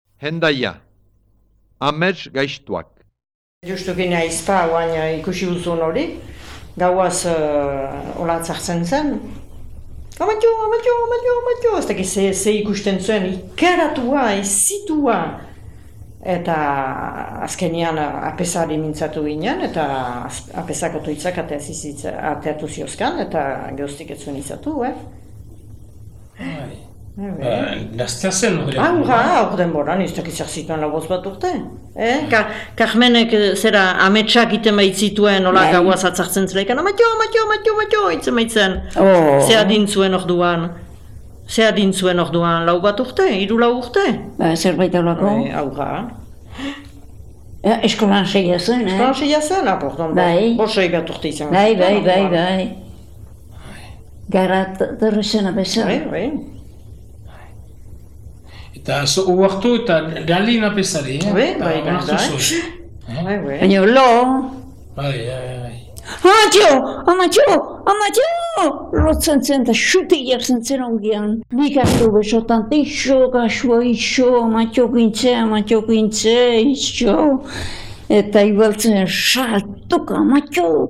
Haurretan amets gaixtoa etortzen zelarik, apeza deitzen zela erraten digute Hendaiako lekukoek: mintzo direnak ama-alabak dira, ama 1900. urtean sorturik.